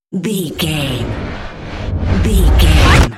Trailer raiser flashback
Sound Effects
Fast paced
In-crescendo
Atonal
futuristic
tension
dramatic
riser
the trailer effect